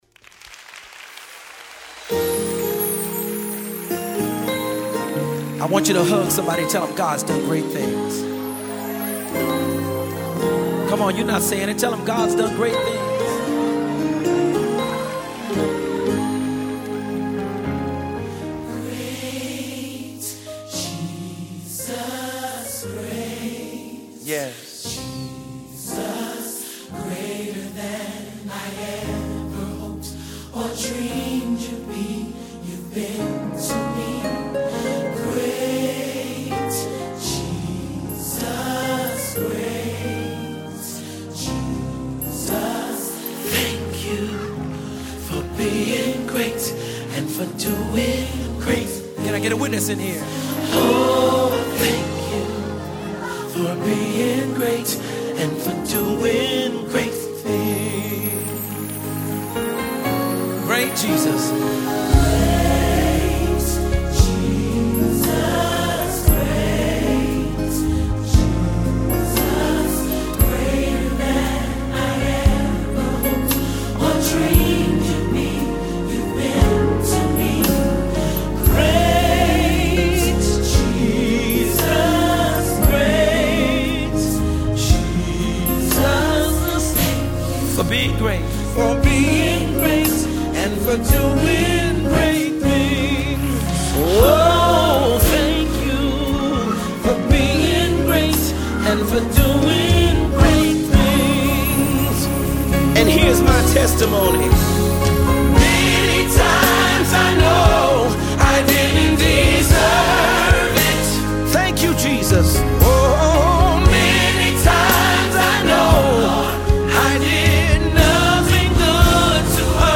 Orchestra/Choir